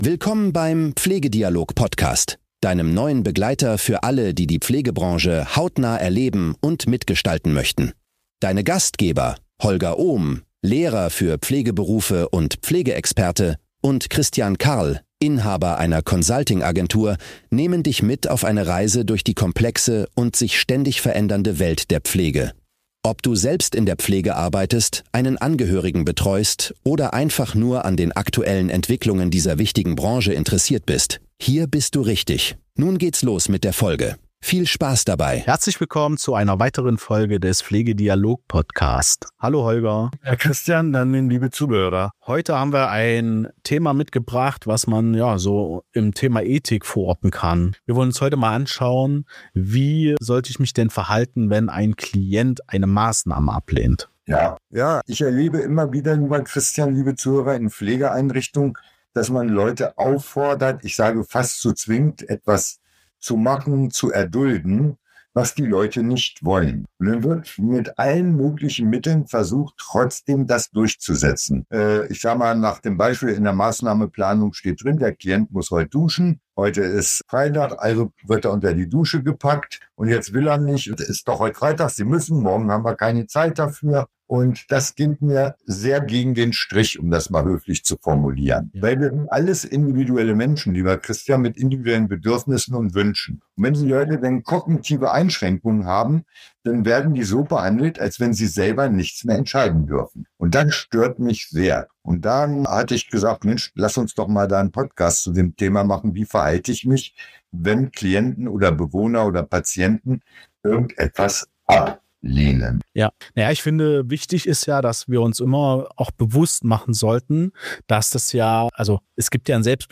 Es geht um natürliche vs. geschäftliche Willensbildung, den Umgang mit kognitiven Einschränkungen, Kommunikationsstrategien in der Praxis und die Verantwortung von Pflegekräften und Führungspersonal. Eine intensive und praxisnahe Diskussion über Respekt, Gerechtigkeit und Dokumentation – mit vielen konkreten Beispielen und Tipps.